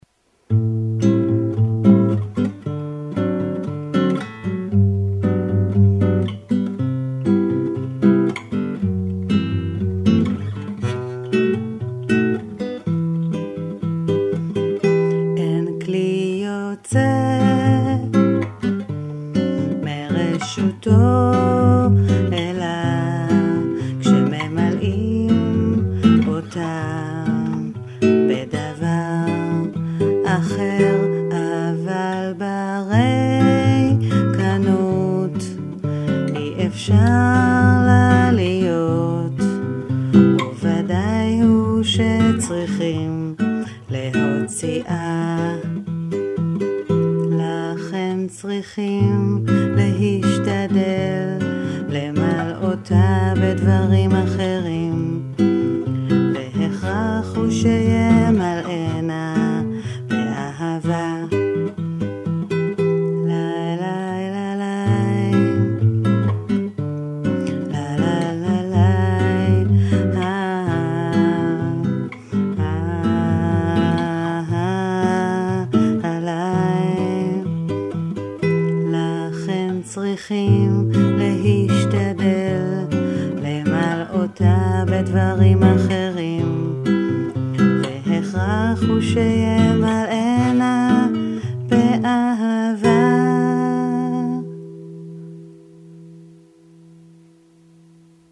פתאום ניזכרתי בנעימה בסגנון ברזילאי שפעם למדתי